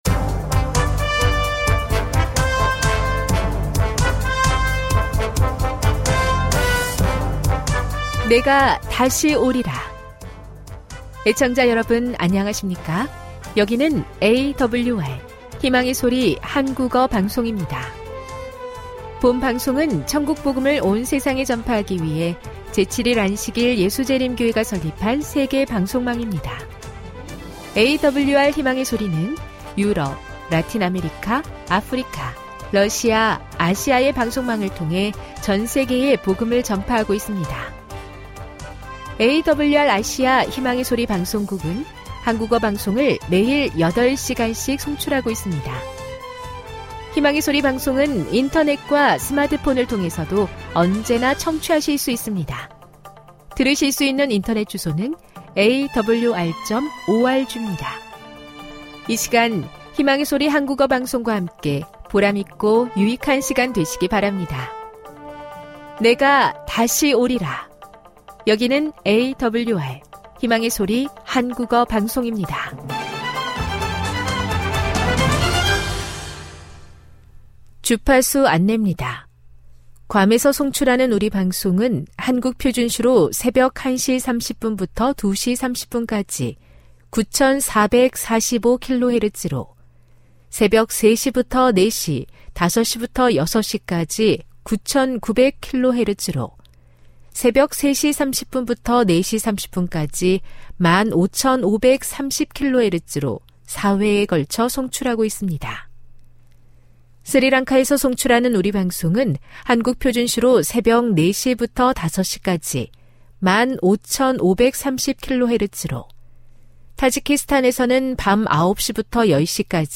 1 안식일대예배 58:28